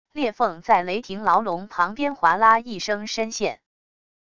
裂缝在雷霆牢笼旁边划拉一声深陷wav音频